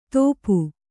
♪ tōpu